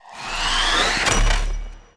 archer_slowing_shot.wav